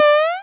MiniJump
Category 🎮 Gaming
8bit chip jump mario NES retro sound effect free sound royalty free Gaming